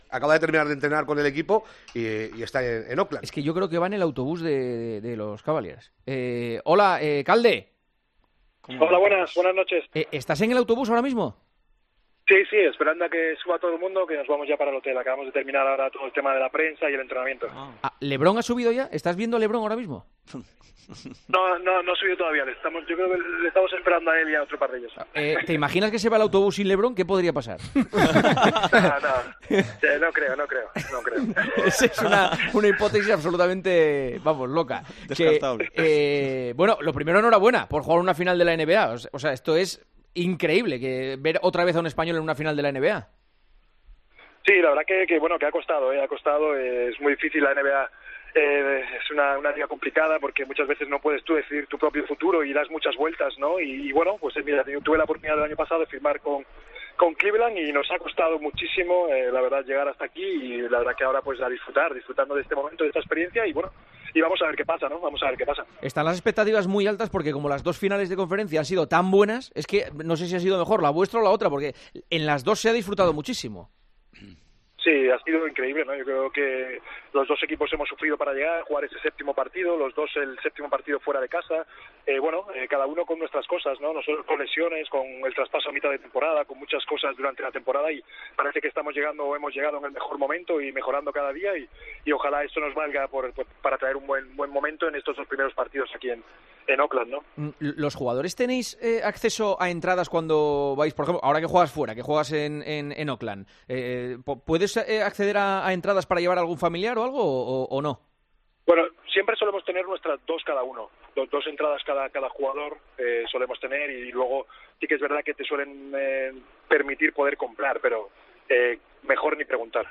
Calderón atendió la llamada de El Partidazo de COPE desde el autobús del equipo: " Ha costado, porque muchas veces no decides tu propio futuro y das muchas vueltas.